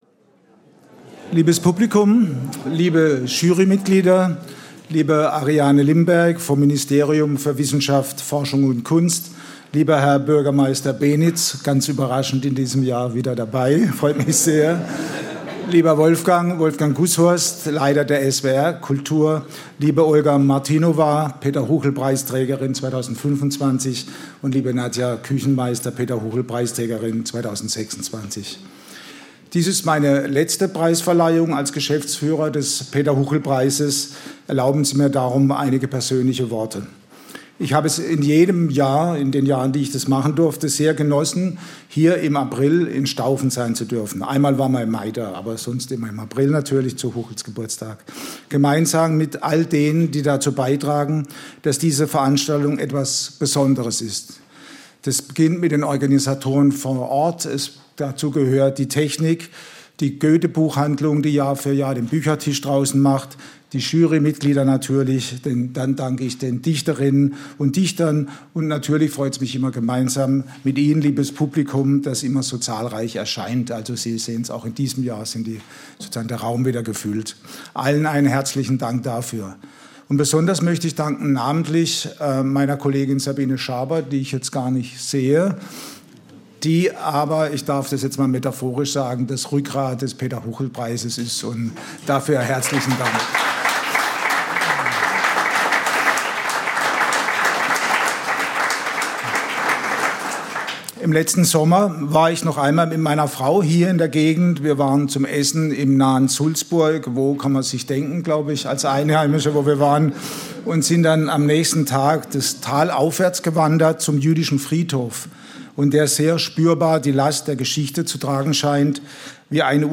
Begrüßung